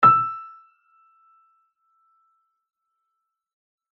E6.mp3